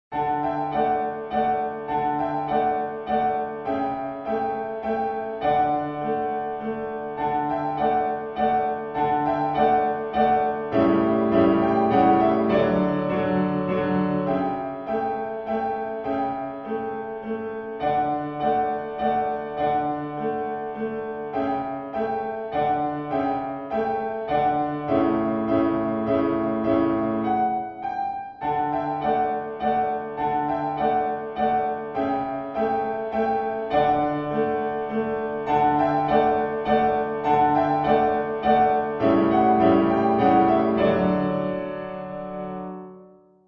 ピアノ練習曲